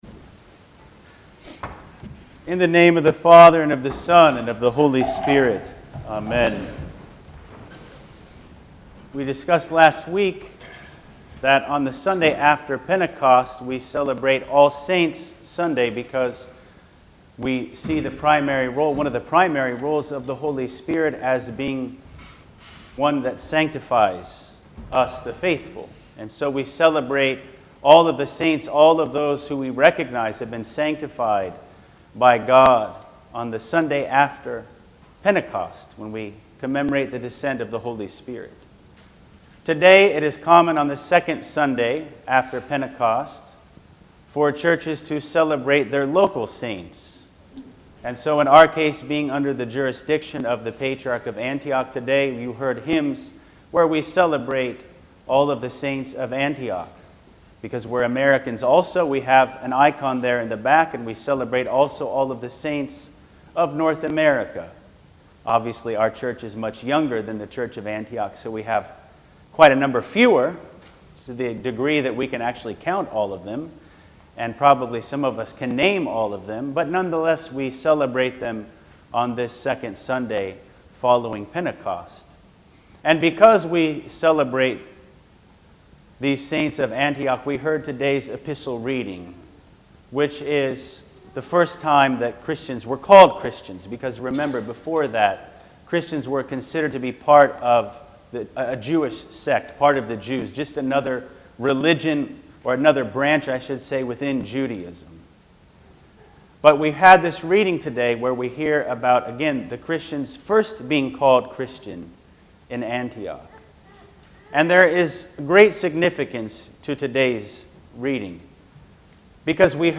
Sermons: October 30, 2022: (5th Sunday of Luke)